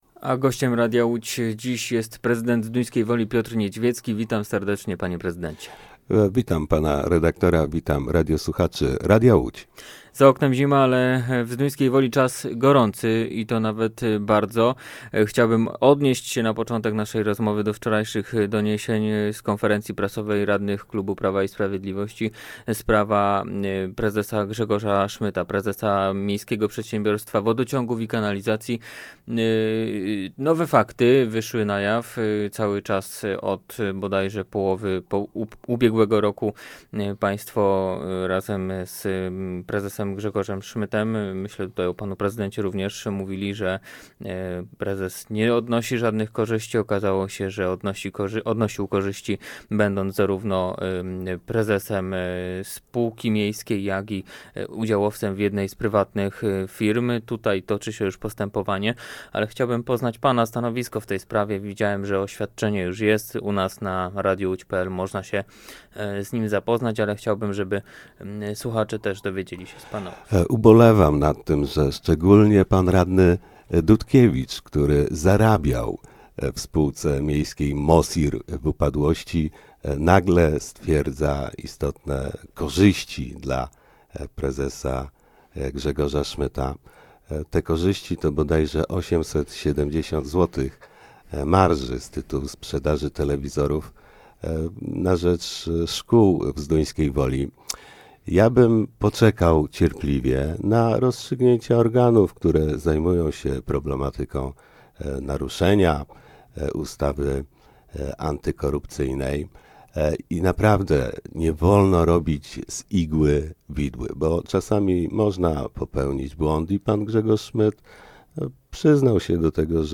Gościem Radia Łódź Nad Wartą był prezydent Zduńskiej Woli, Piotr Niedźwiecki.